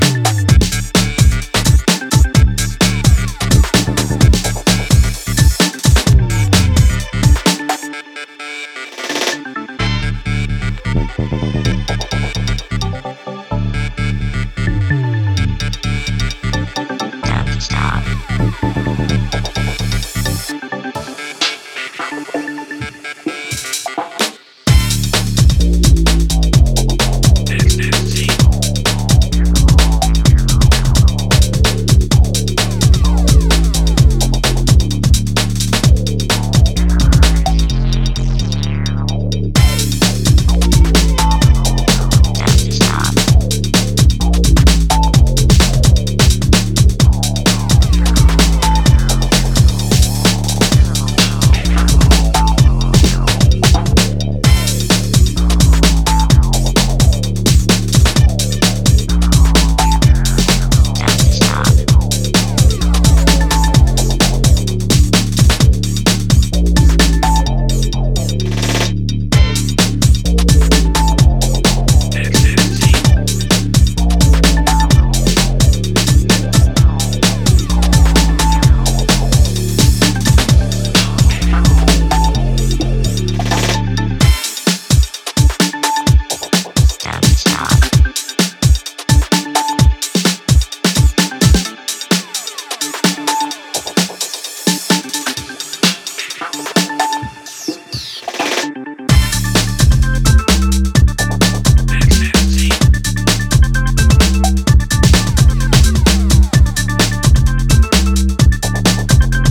UKG or breaks